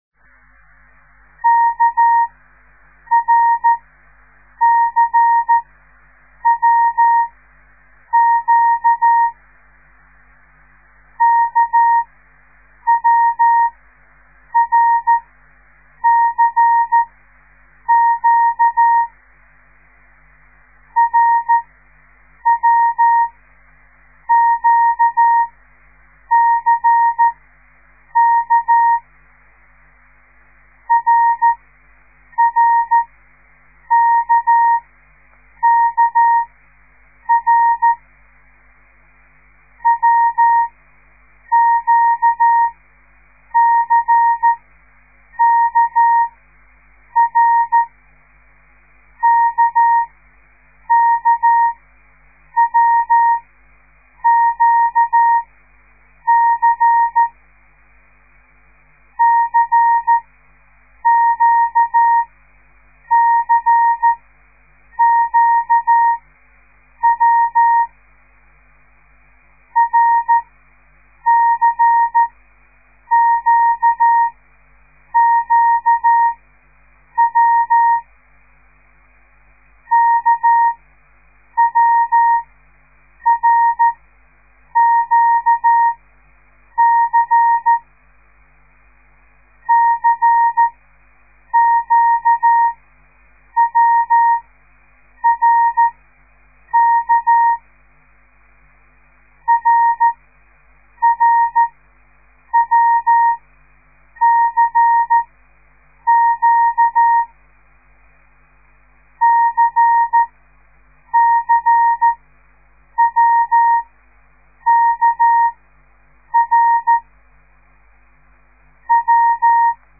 De geluidsfiles bestaan uit geseinde text in letter of cijfergroepen , steeds groepen van 5 tekens en iedere les bestaat uit 25 groepen
De letter K  dah-di-dah  / de letter R di-dah-dit / de letter C dah-di-dah-dit / de letter W di-dah-dah / de letter Q dah-dah-di-dah